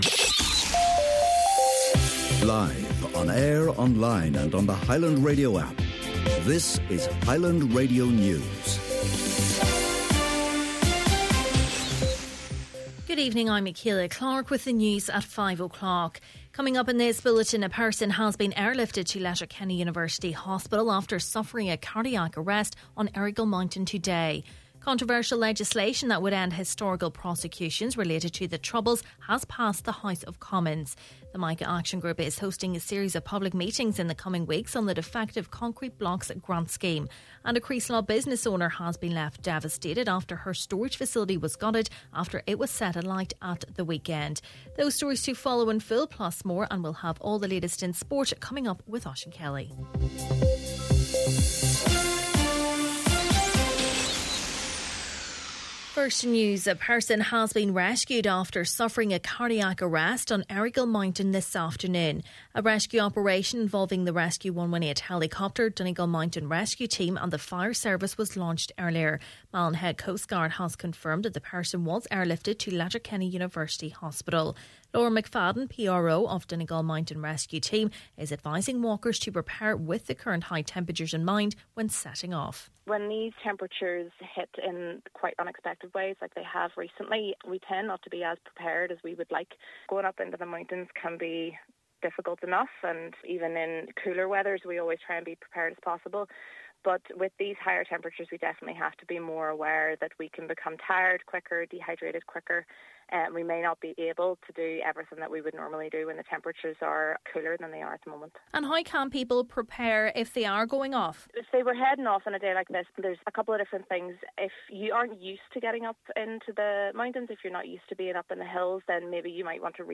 Main Evening News, Sport and Obituaries – Wednesday September 6th